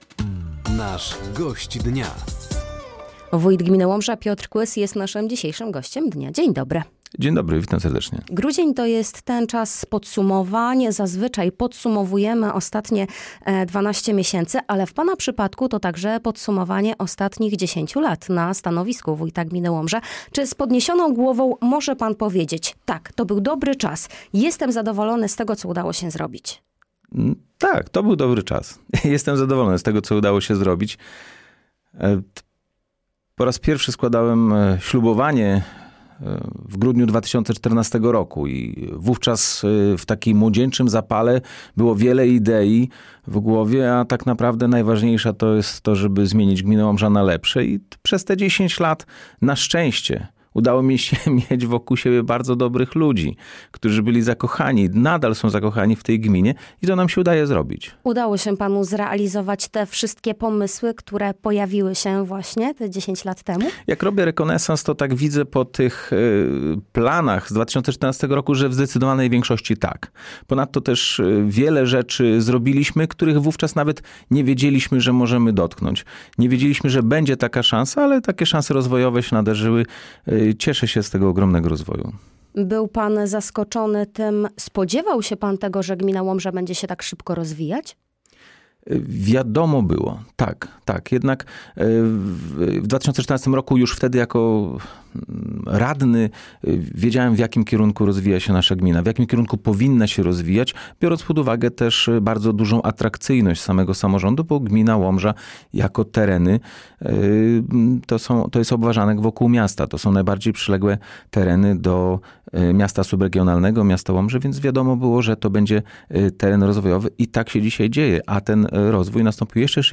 Studio odwiedził wójt gminy Łomża, Piotr Kłys.